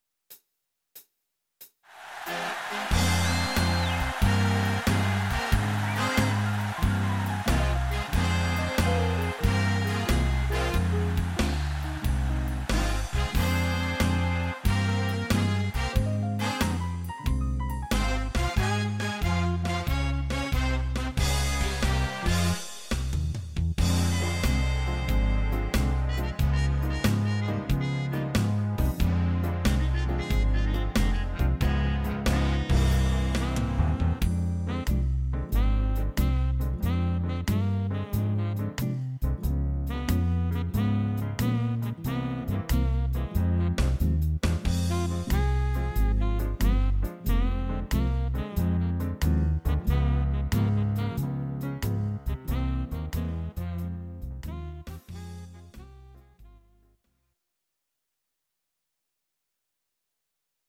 Audio Recordings based on Midi-files
Pop, Oldies